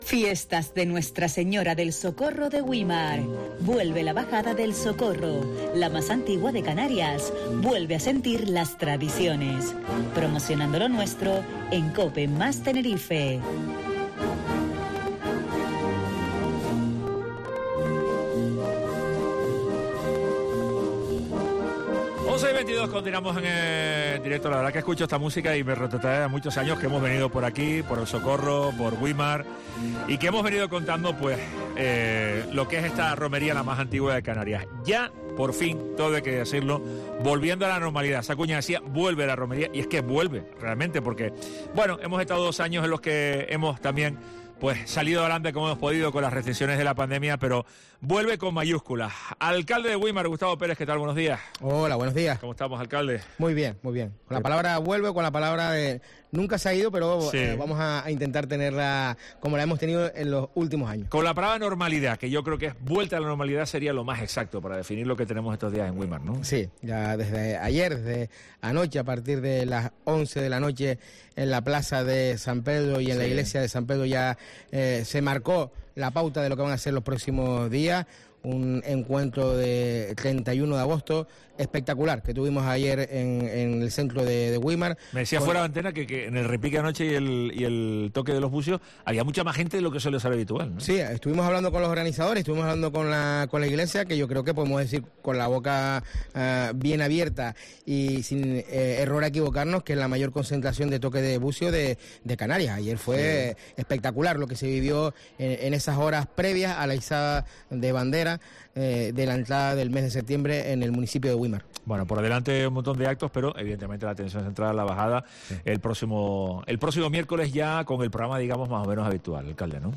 Entrevista Gustavo Pérez, Alcalde Güimar